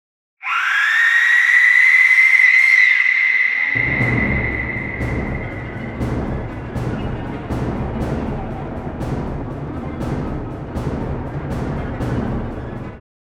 Opening with an Aztec Death Whistle, this sound instils fear into the audience and sets the tone for dinner.